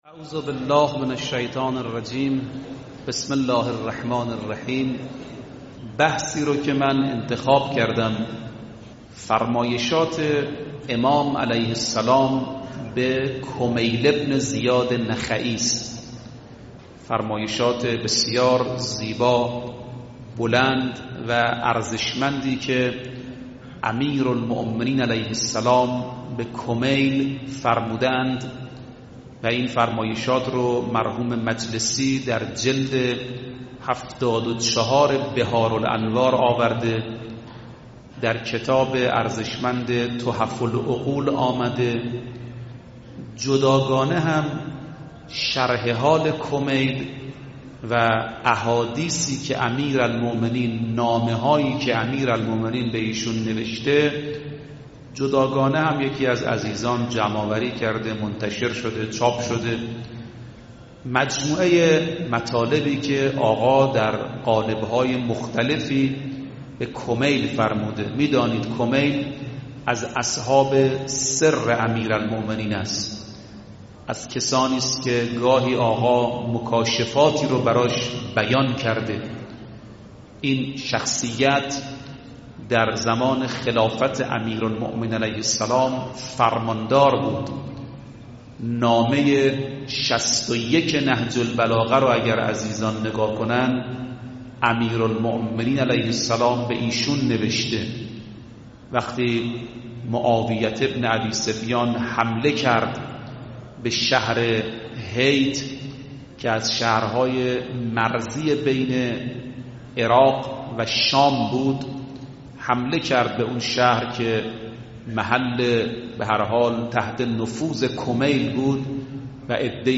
مدت زمان سخنرانی : 31 دقیقه